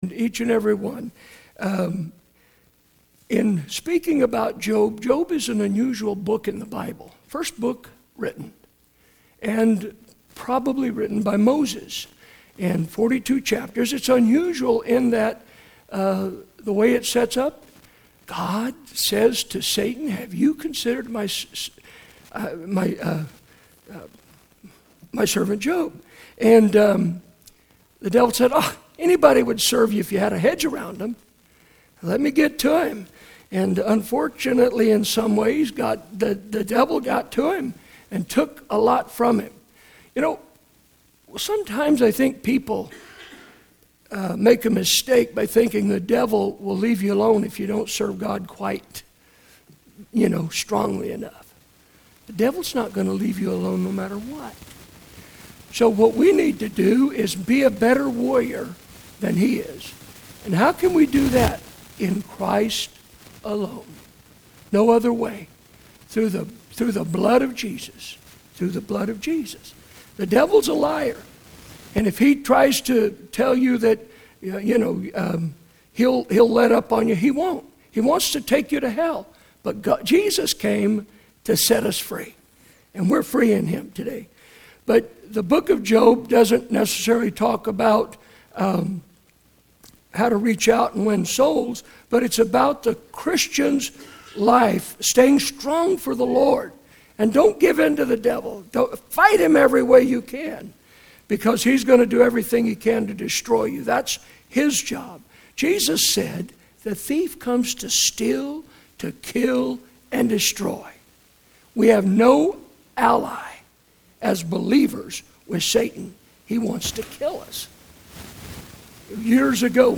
Morning Sermons